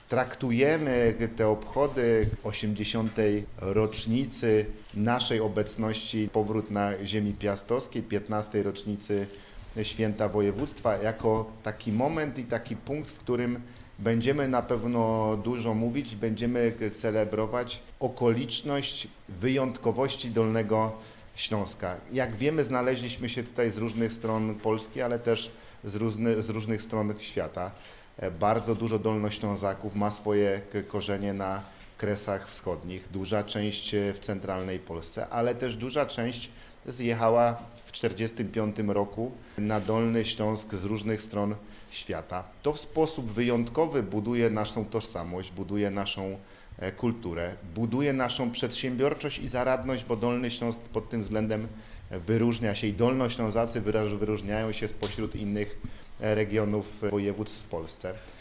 – Jesteśmy wyjątkowi. Dolny Śląsk jest wyjątkowy pod wieloma względami- podkreśla Paweł Gancarz, Marszałek Województwa Dolnośląskiego, mówiąc o tegorocznych obchodach Święta Województwa.
Marszałek zwraca uwagę, że tegoroczne obchody mają nie tylko charakter uroczysty, ale też refleksyjny.